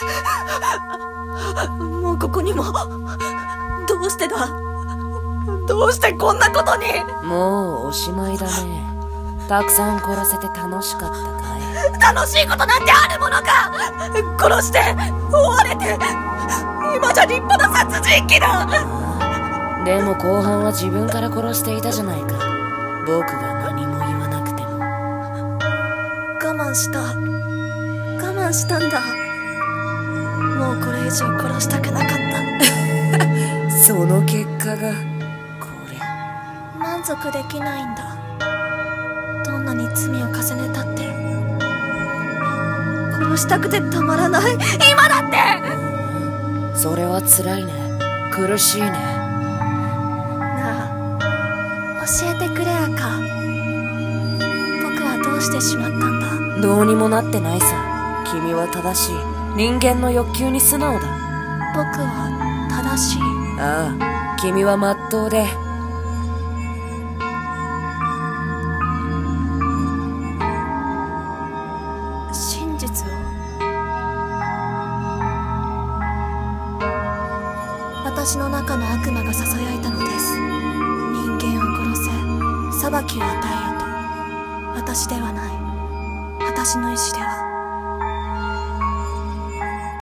声劇台本【殺人鬼の供述】